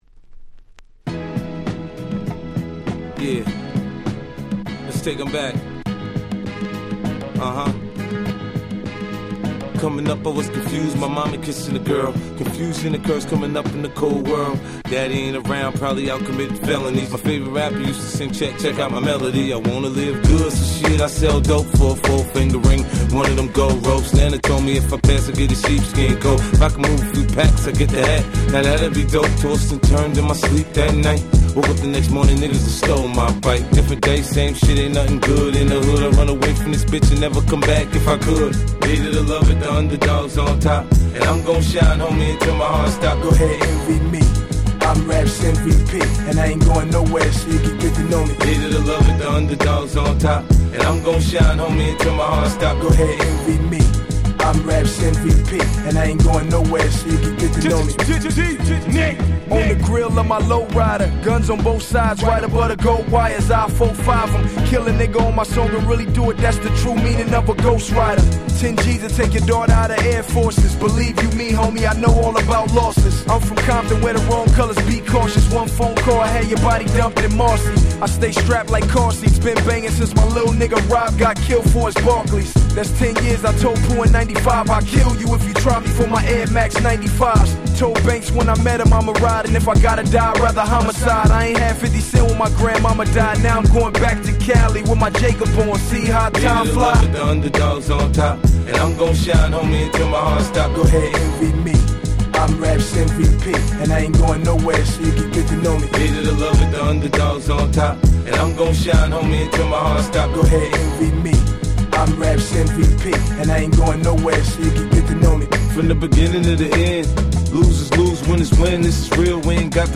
05' Big Hit Hip Hop !!
説明不要の00's Hip Hop Classicsです！！！